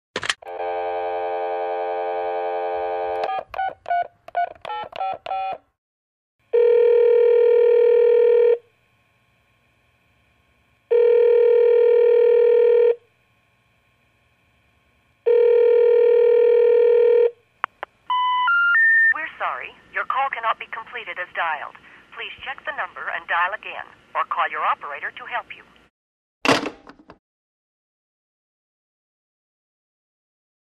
Dialing Phone | Sneak On The Lot
Pickup Phone From Hook, Dial Tone, Push Button Tones / Recorded Message, "call Cannot Be Completed" / Hang Up.